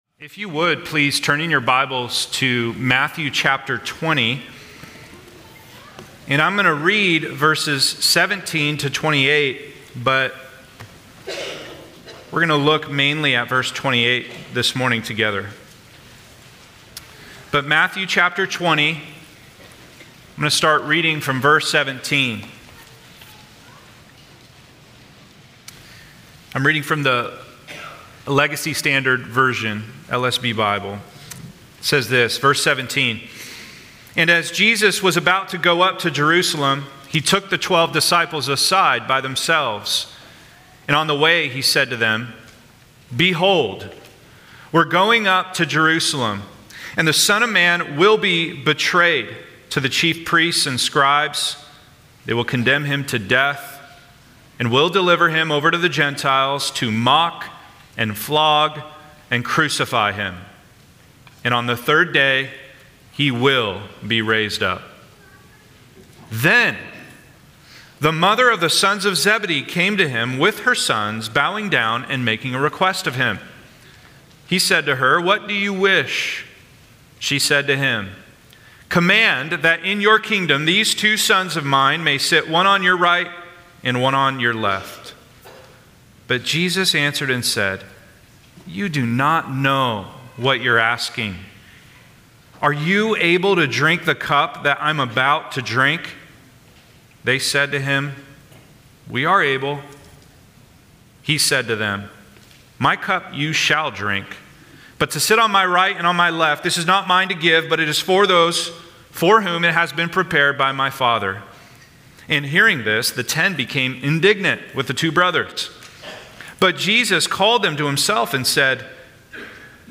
These sermons were preached on Sunday morning during our corporate worship service.
Sermons
sermon-8-17-25.mp3